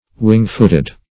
wing-footed - definition of wing-footed - synonyms, pronunciation, spelling from Free Dictionary
Wing-footed \Wing"-foot`ed\, a.